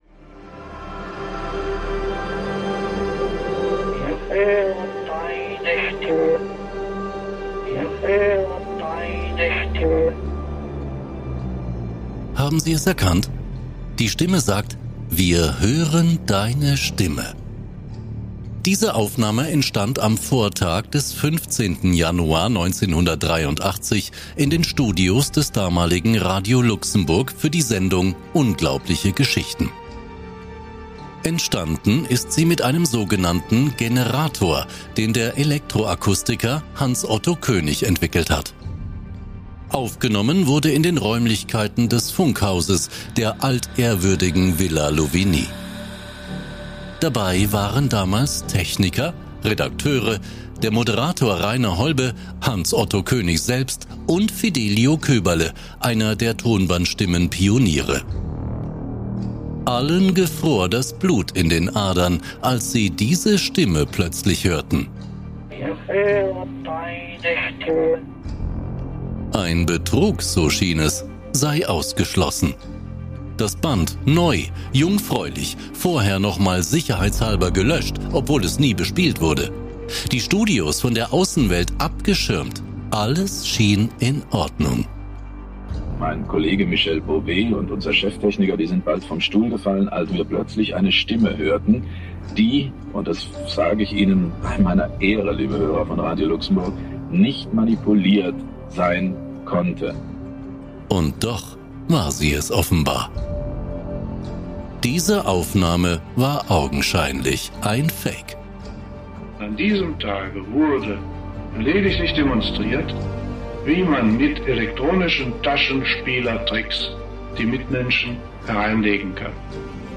Ich lasse die damaligen Originalaufnahmen wiederaufleben, erkläre die Hintergründe und zeige, warum sich der vermeintliche Durchbruch später als Täuschung herausstellte.